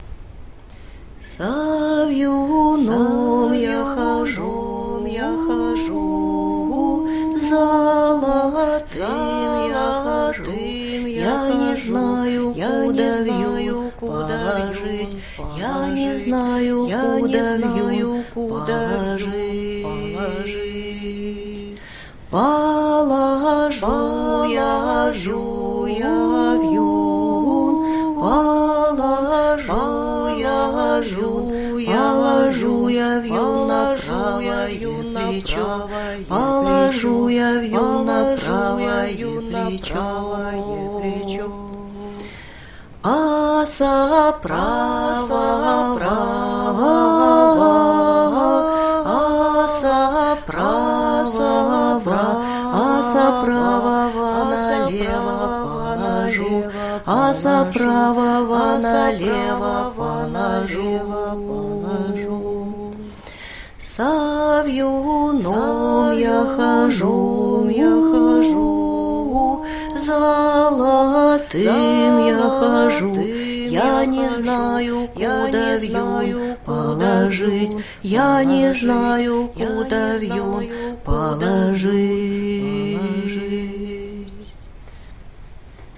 ../icons/sormliri.jpg   Русская народная песня